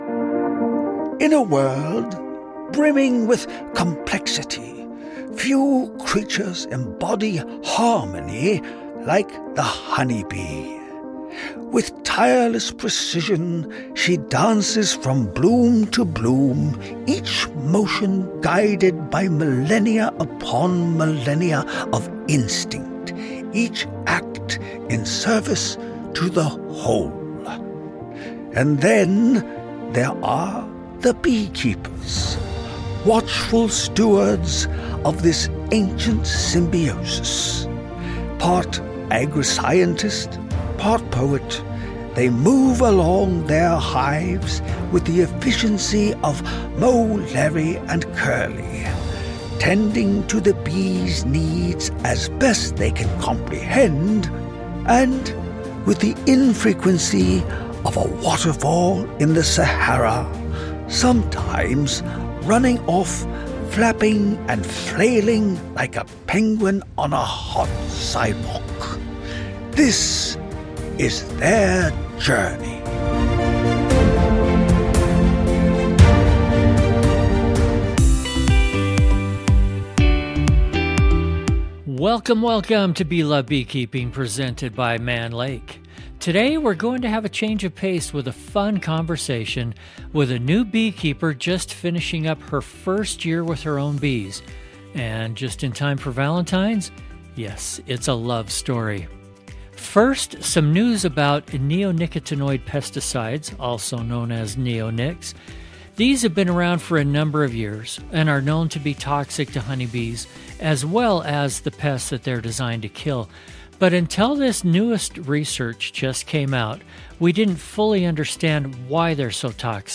candid and joyful conversation